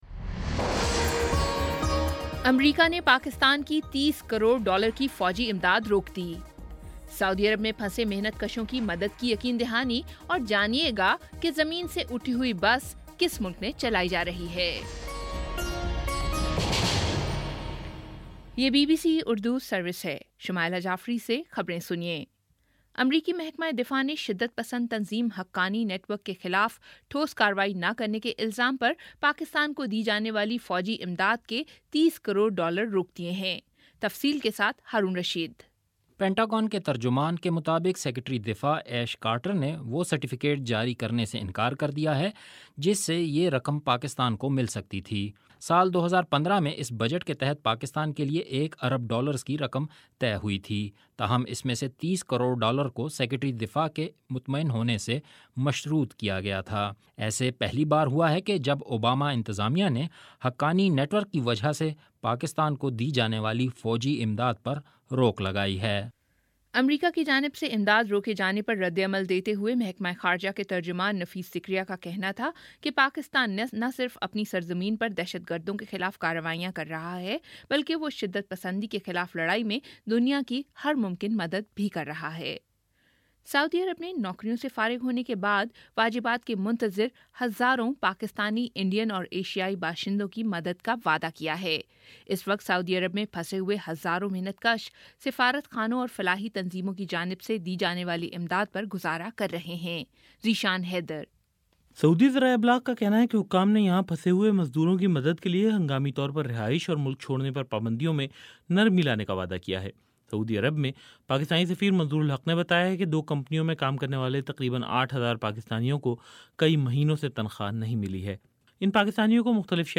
اگست 04 : شام چھ بجے کا نیوز بُلیٹن